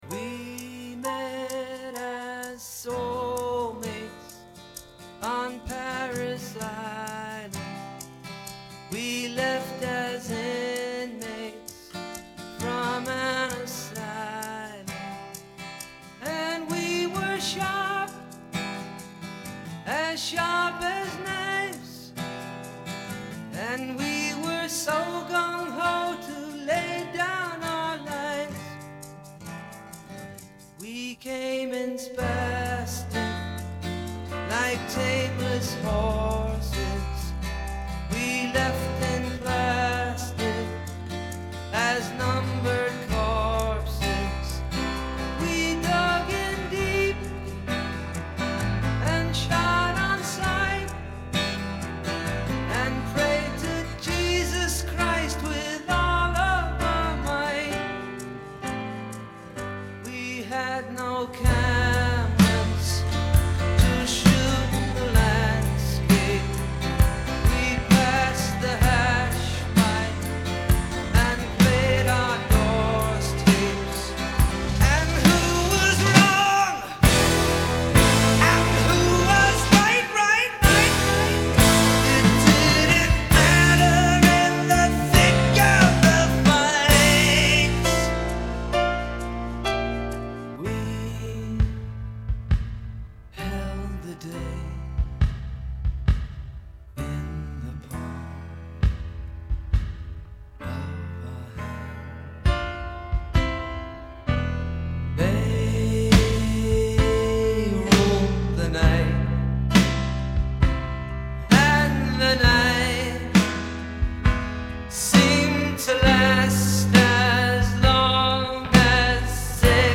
Edited from 7:03 to 3:00. Try to find all the edits.